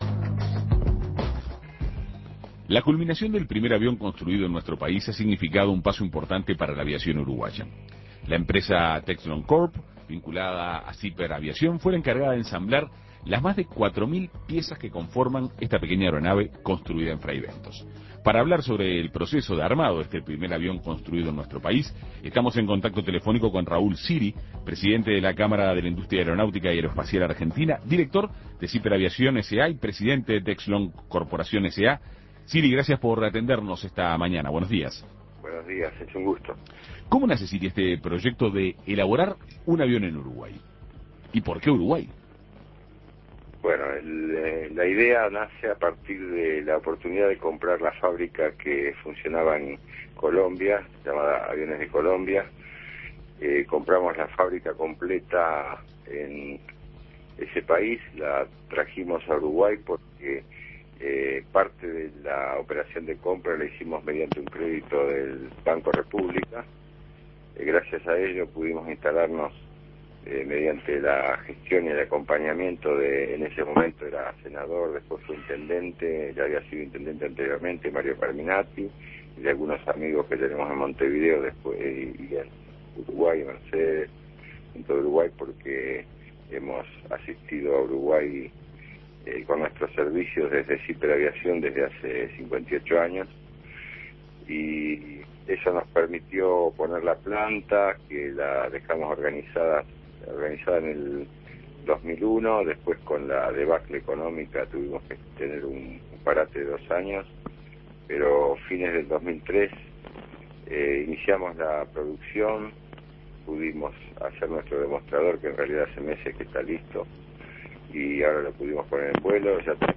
Una empresa fraybentina fue la responsable del armado del primer avión construido en Uruguay, cuyas unidades serán exportadas a todo el mundo. Escuche la entrevista